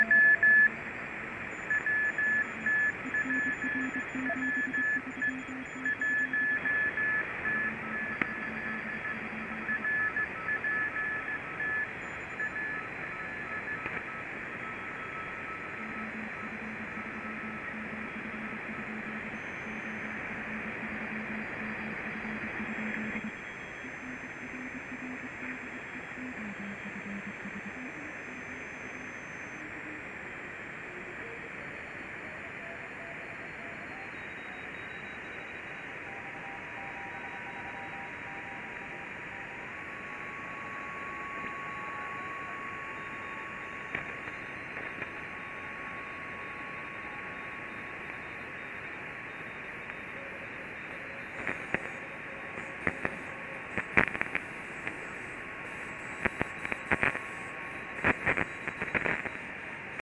ricetrasmettitore SSB QRP in 20m
I file sono dei piccoli MP3 da uno o due minuti... c'è fonia, cw e rtty...
l'antenna è il dipolaccio ex_ricevitore, in pratica un pezzo di filo steso sul tetto,